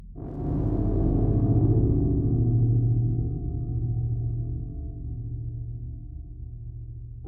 deep bass rumble 3
ambience atmosphere bomb boom cinematic city dark electro sound effect free sound royalty free Movies & TV